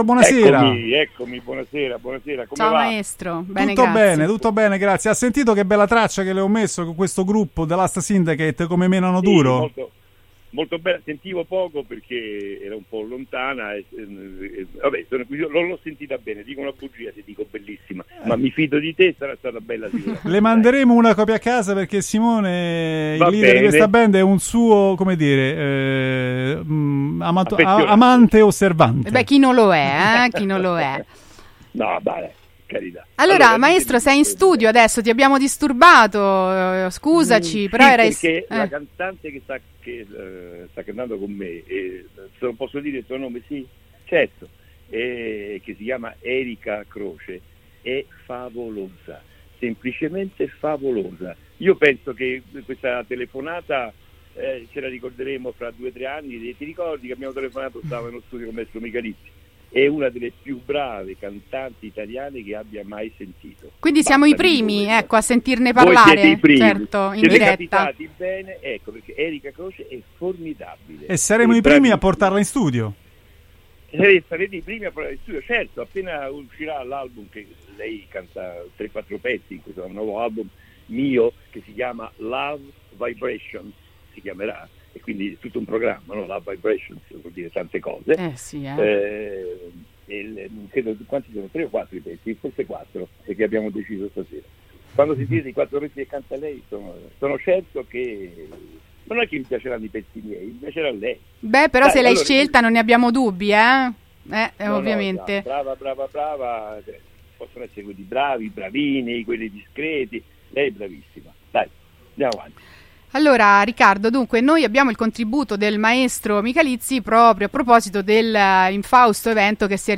Interviste NON SMORZA’